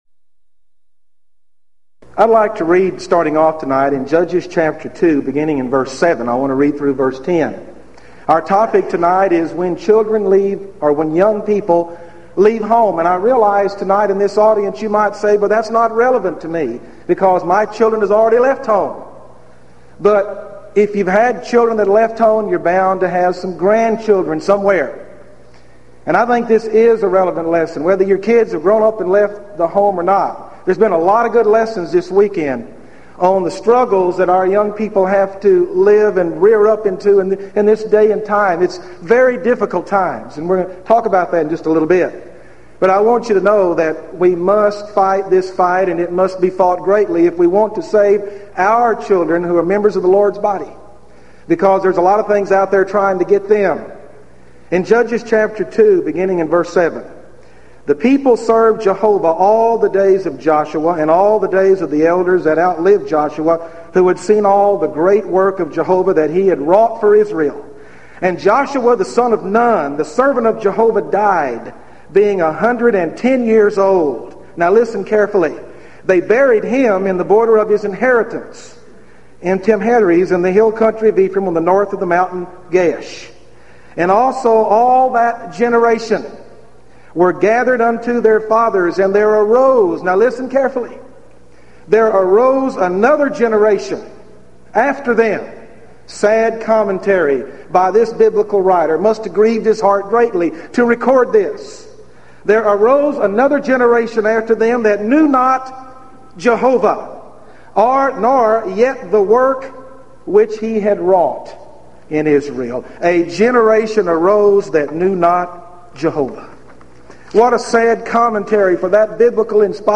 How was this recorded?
Event: 1996 Gulf Coast Lectures Theme/Title: Lively Issues On The Home And The Church